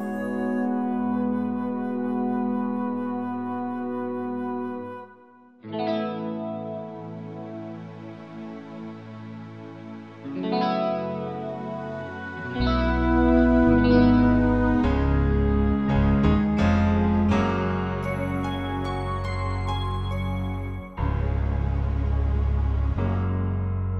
no Backing Vocals Musicals 5:48 Buy £1.50